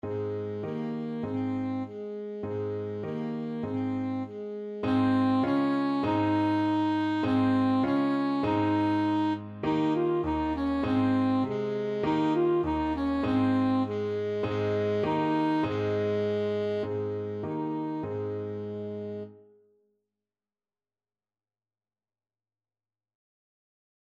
Traditional Trad. Frere Jacques Alto Saxophone version
Alto Saxophone
Eb major (Sounding Pitch) C major (Alto Saxophone in Eb) (View more Eb major Music for Saxophone )
4/4 (View more 4/4 Music)
Traditional (View more Traditional Saxophone Music)
frere_jac_ASAX.mp3